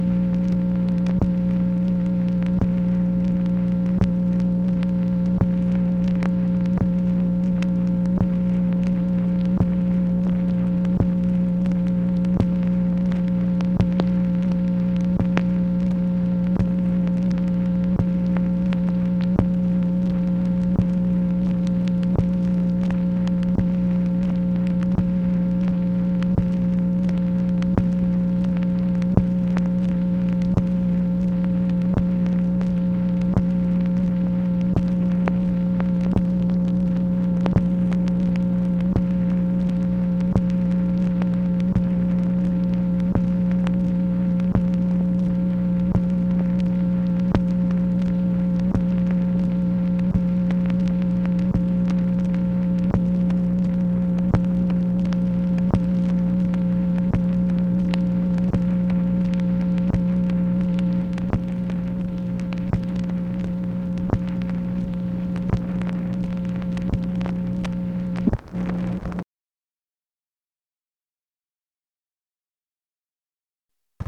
MACHINE NOISE, March 9, 1966
Secret White House Tapes | Lyndon B. Johnson Presidency